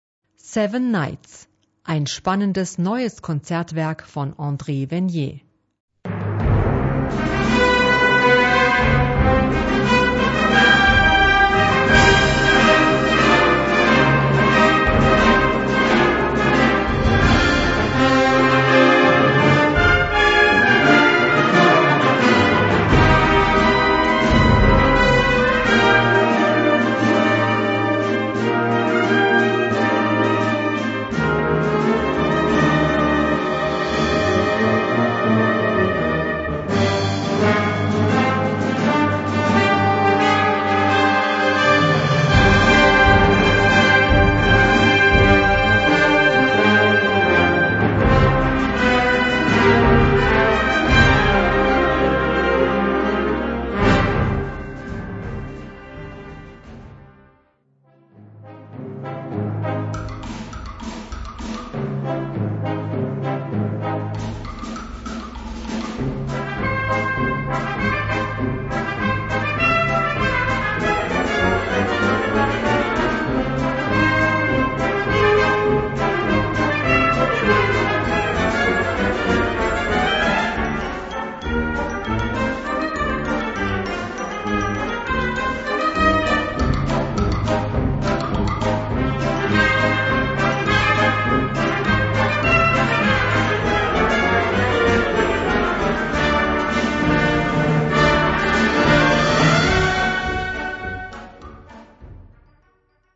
Categoría Banda sinfónica/brass band
Subcategoría Música de concierto / Música sinfónica
Instrumentación/orquestación Ha (banda de música)
La música evoca batallas épicas y hazañas heroicas.